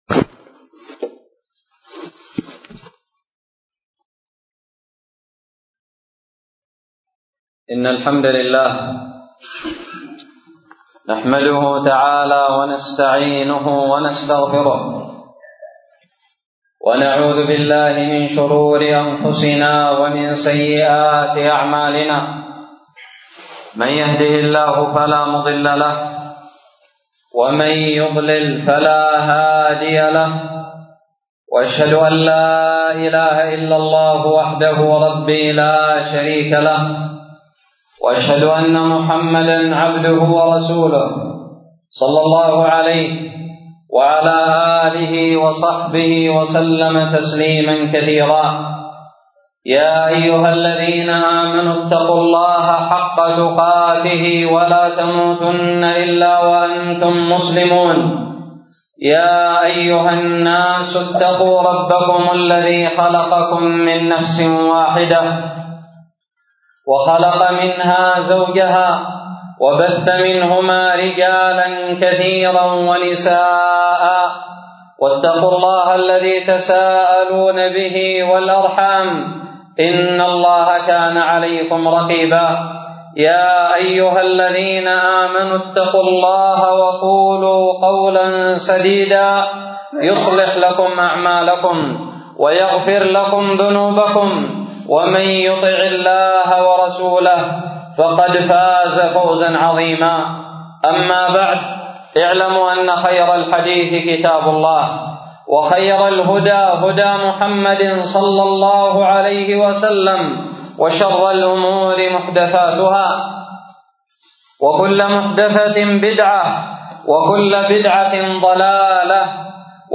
خطب الجمعة
ألقيت بدار الحديث السلفية للعلوم الشرعية بالضالع في 3 ربيع أول 1438هــ